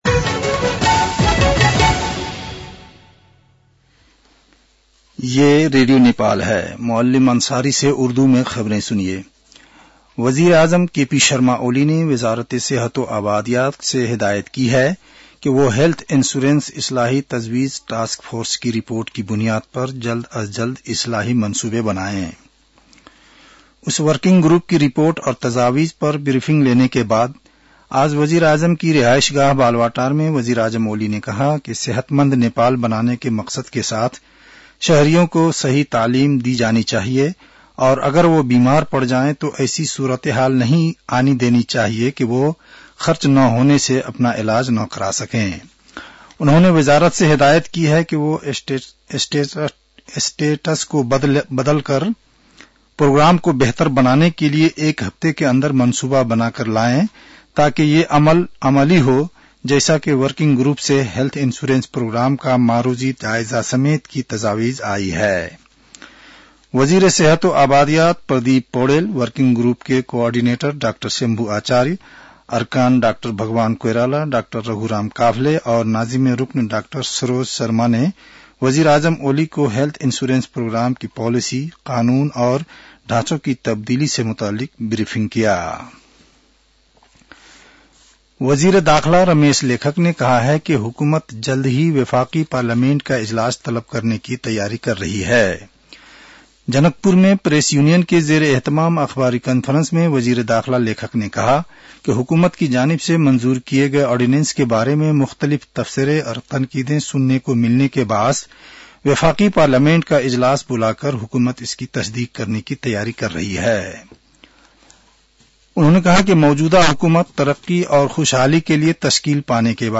उर्दु भाषामा समाचार : ७ माघ , २०८१
Urdu-news-10-06.mp3